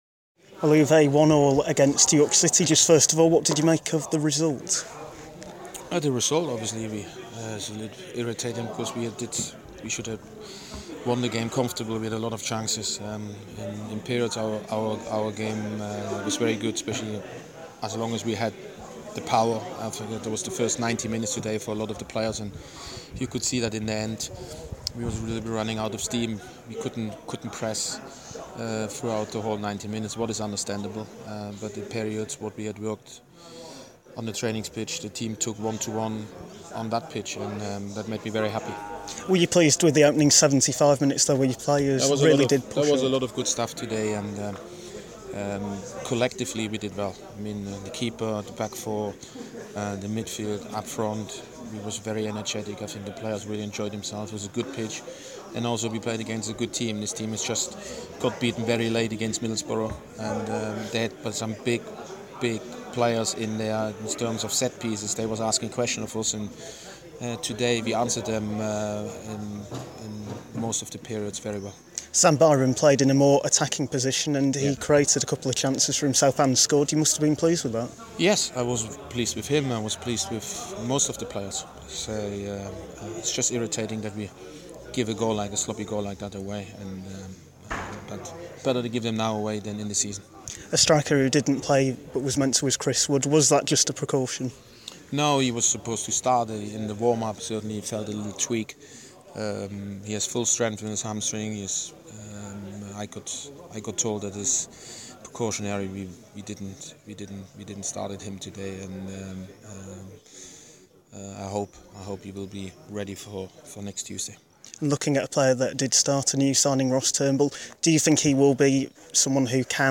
spoke to the Leeds United Head Coach after the 1-1 draw with York.